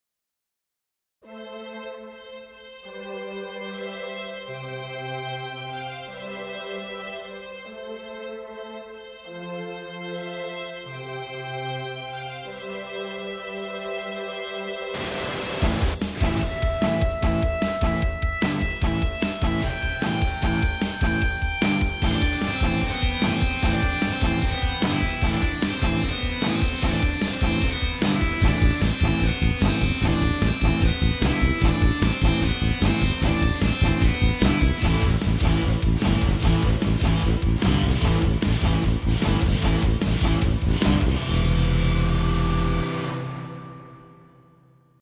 BGM
ショートロック